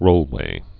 (rōlwā)